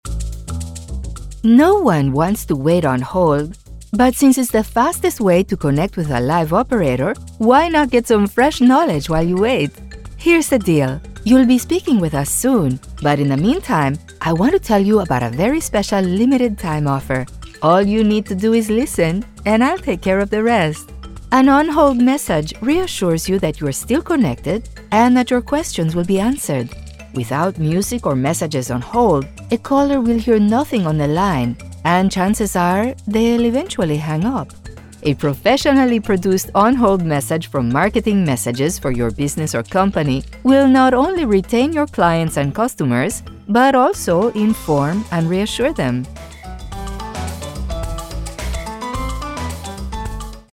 Voice Diversity Sample Audio
Spanish-accented English Voices: